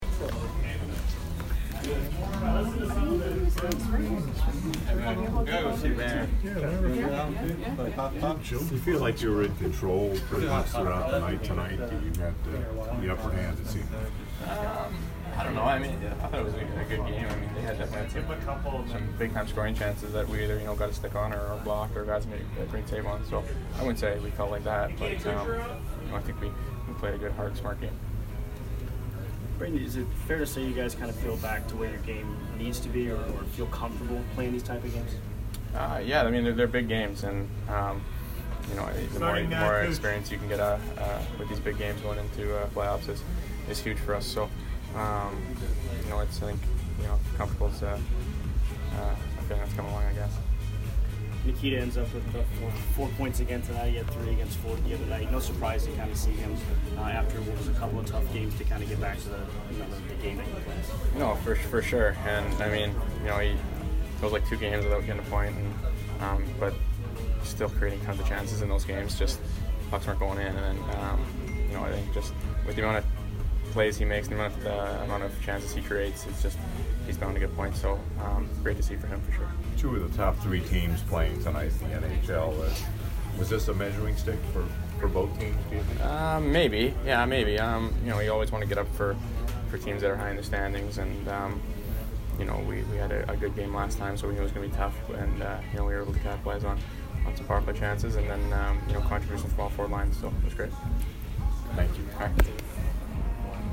Brayden Point post-game 2/12